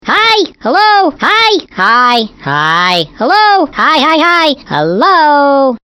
Hi, Hello is a hoops&yoyo greeting card with sound made for saying hi to someone.